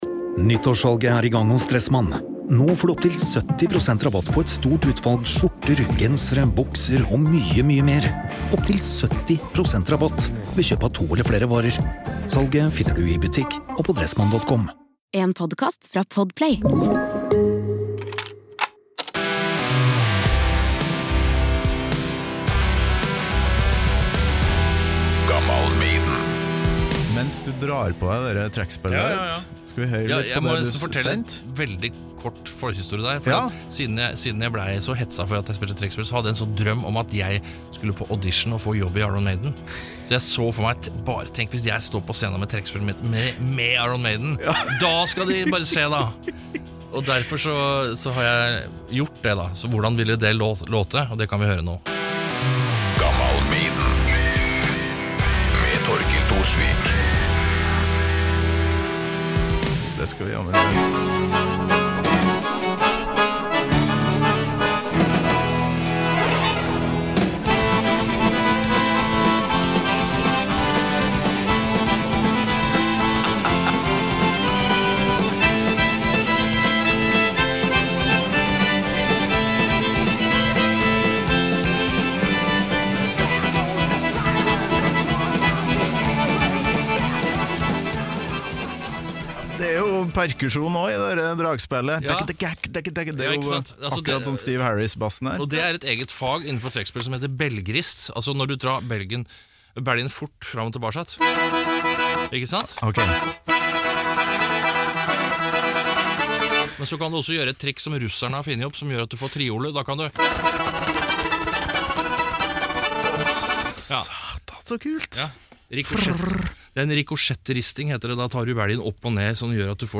Gammal Moro: Stian Carstensen spiller Aces High på Stalin-orgel og tisser på "traugutt" i San Fransisco sammen med Mike Patton